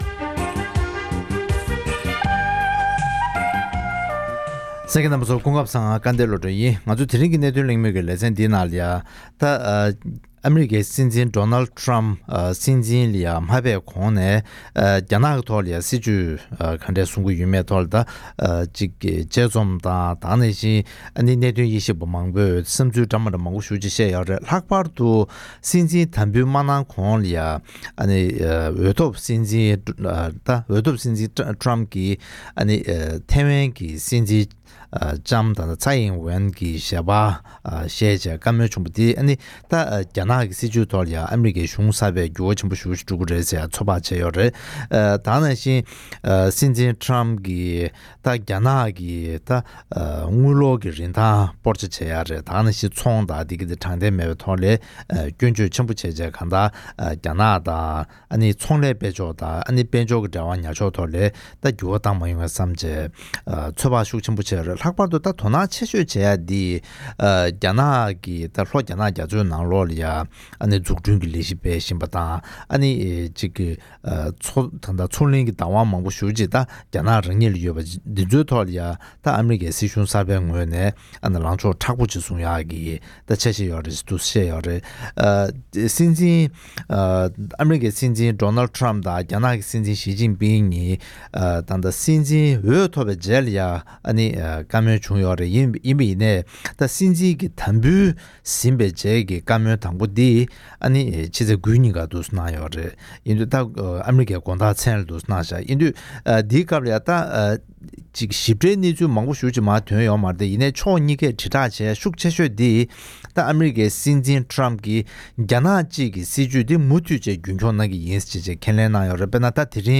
ཨ་རིའི་སྲིད་འཛིན་གསར་པ་ཌོ་ནལཌ་ཊམ་མཆོག་གི་གཞུང་འཛིན་འོག་ཨ་རི་དང་རྒྱ་ནག་བར་གྱི་འབྲེལ་བ་དང་འཕེལ་རིམ་སོགས་ཀྱི་ཐད་གླེང་མོལ་གནང་བ།